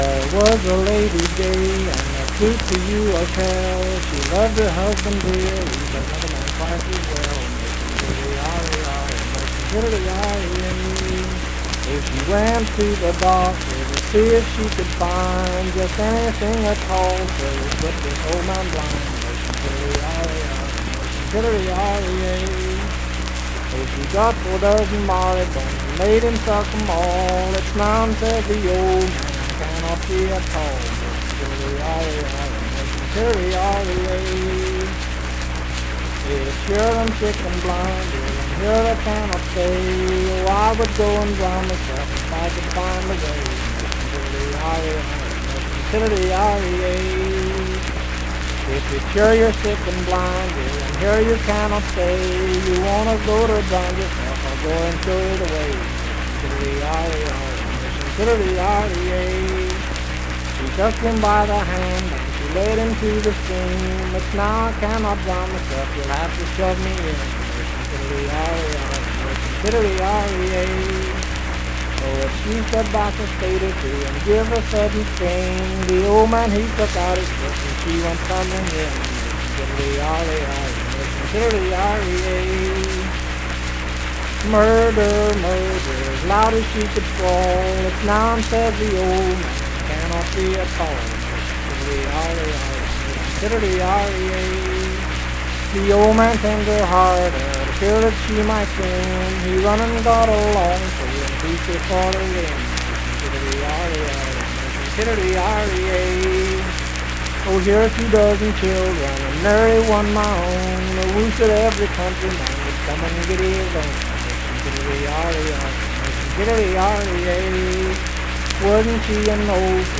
Unaccompanied vocal music
Verse-refrain 11(4)&R(2).
Voice (sung)